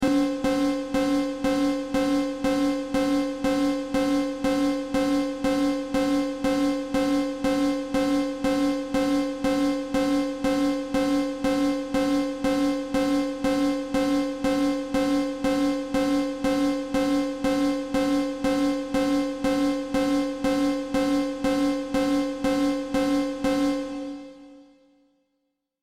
دانلود آهنگ هشدار 16 از افکت صوتی اشیاء
دانلود صدای هشدار 16 از ساعد نیوز با لینک مستقیم و کیفیت بالا
جلوه های صوتی